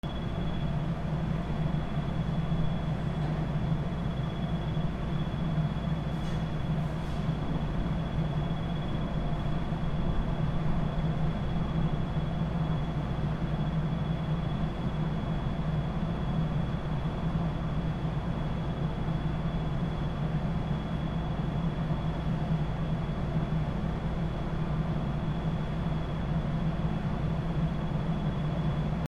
/ B｜環境音(自然) / B-10 ｜波の音 / 波の音
フェリー乗り場 高松 フェリー出港前
ゴー